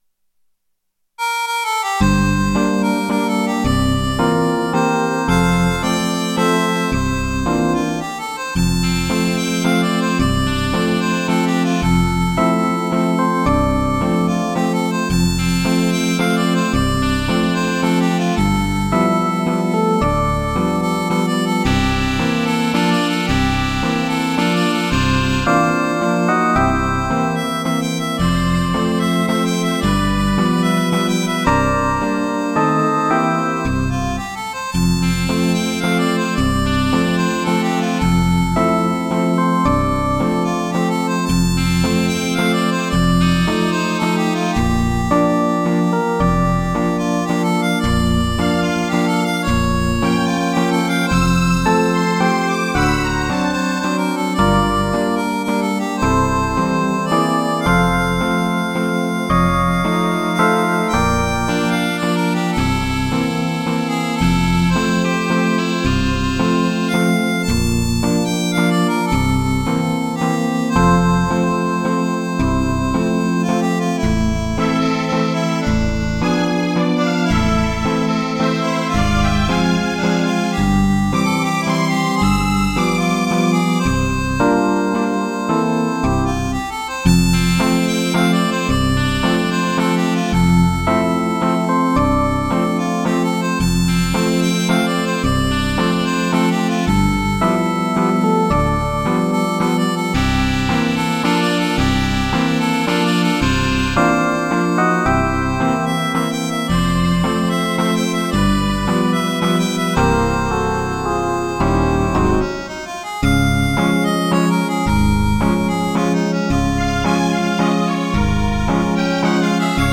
Медленный вальс